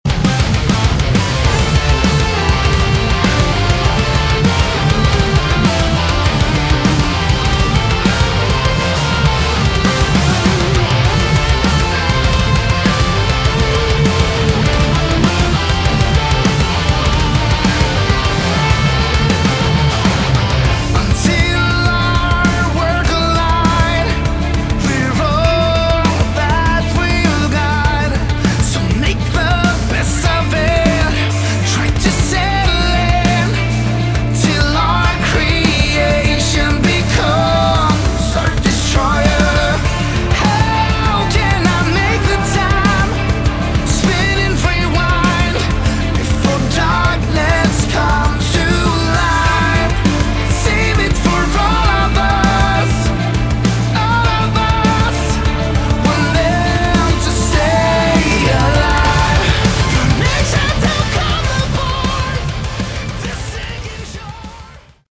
Category: Melodic Metal
guitars, bass, keyboard, vocals
drums